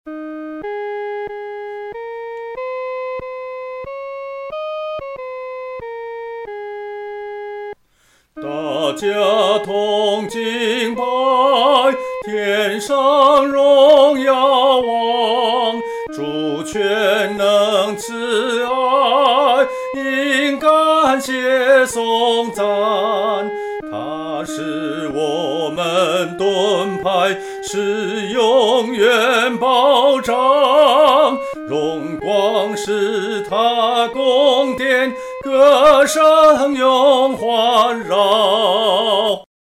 独唱（第一声）
来敬拜荣耀王-独唱（第一声）.mp3